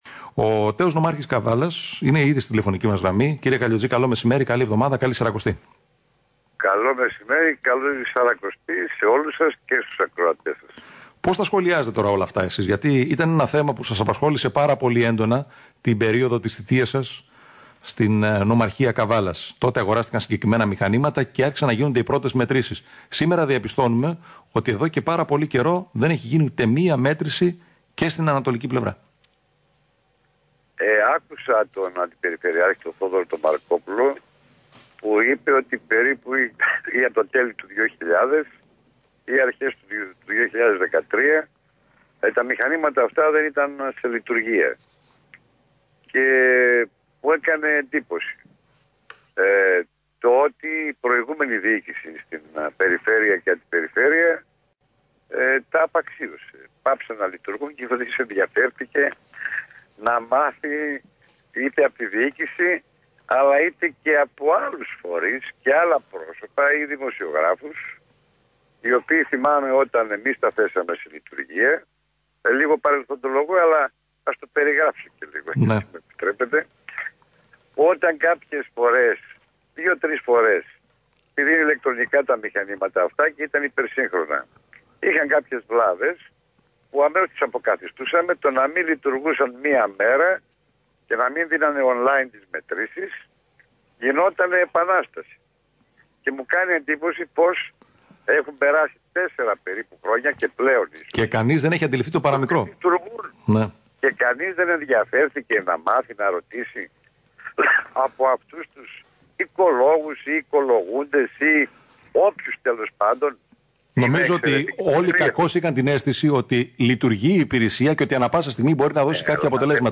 Ο τ. Νομάρχης Καβάλας μίλησε στο ALPHA Radio 88.6